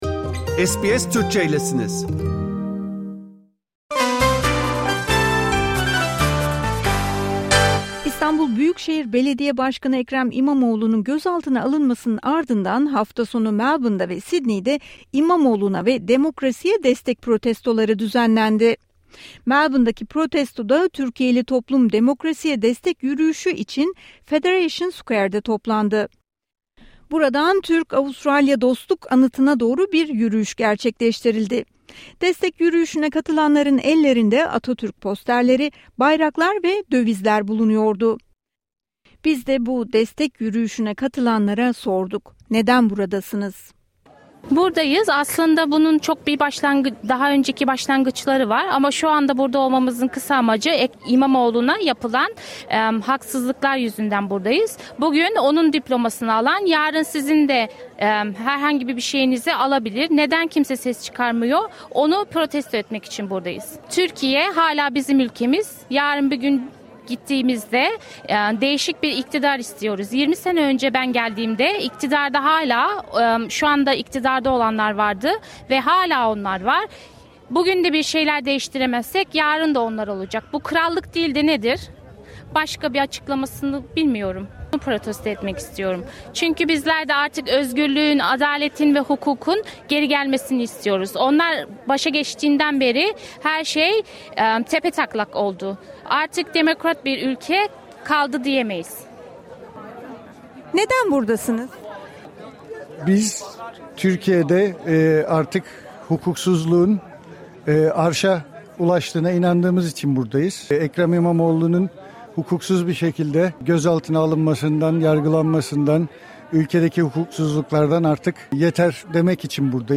Avustralyalı Türkler, İBB Başkanı Ekrem İmamoğlu'nun tutuklanmasını Melbourne ve Sydney'de düzenlenen demokrasi buluşmalarıyla protesto etti. Eylem ve yürüyüşlerde "hak hukuk adalet" sloganı atıldı, dövizler taşındı.
Protestolara katılanlar, o gün neden orada bulunduklarını SBS Türkçe mikrofonuna anlattı.